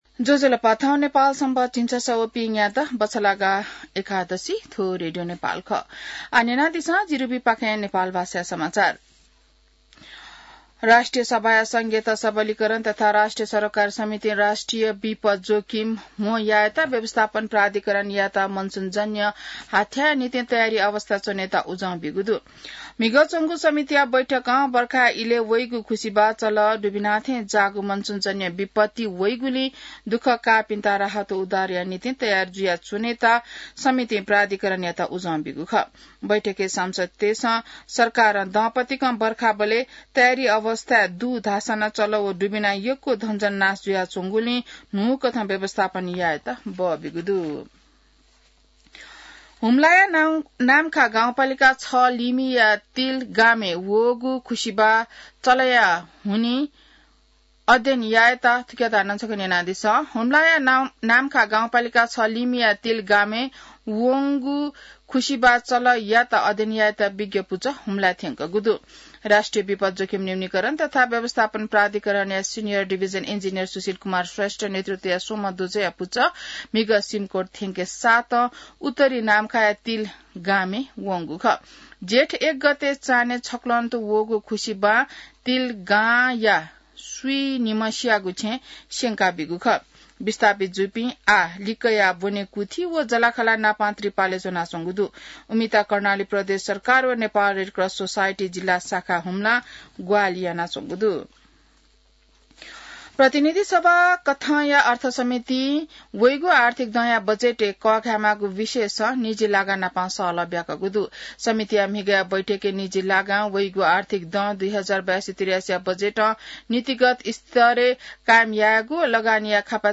नेपाल भाषामा समाचार : ९ जेठ , २०८२